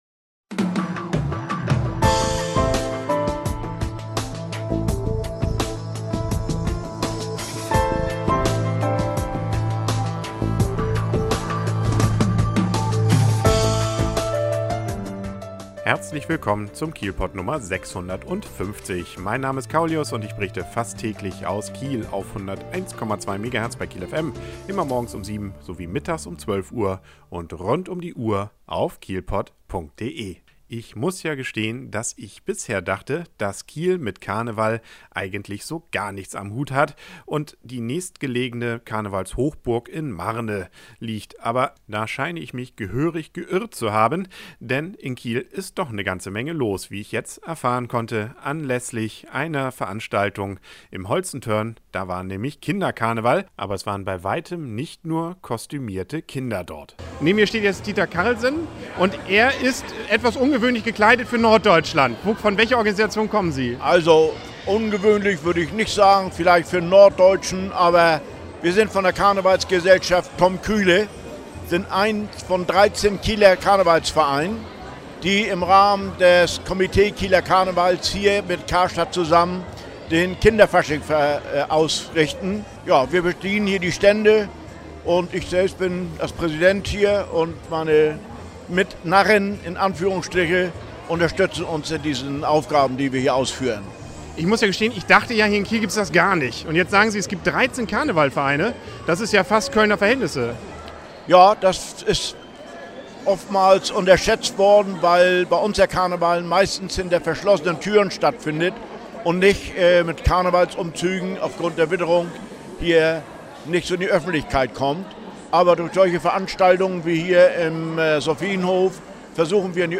Ich war beim Kinderkarneval im Holstentörn und habe bei einigen Interviews festgestellt, dass Kiel doch eine Karneval-Hochburg ist.